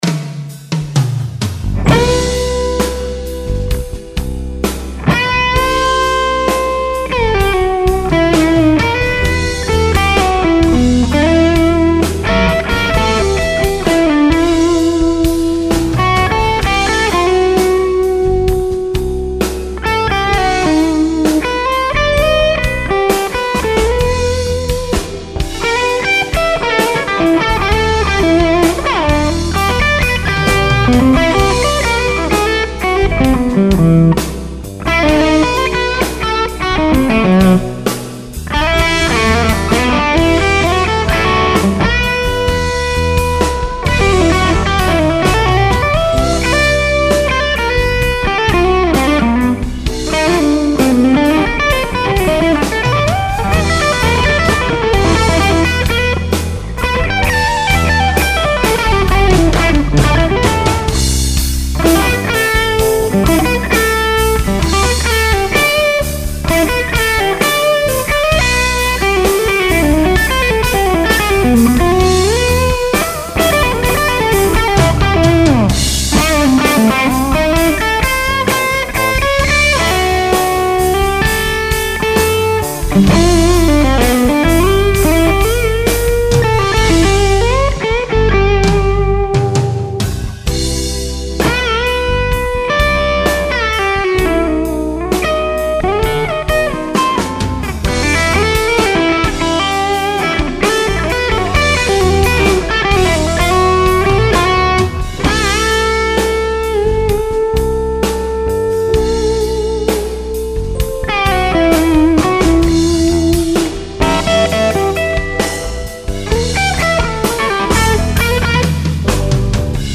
This is Skyline w/BM power and modded PAB. HRM was treble 150K from cap to wiper, bass 30%, mids 59%
I like this better, "tighter" I would say, but doesn't seem to want to take off like the other clip did.
That's second clip is great but sounds like the upper mids don't breathe as well as the first.